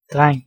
Ääntäminen
US Tuntematon aksentti: IPA : /læd/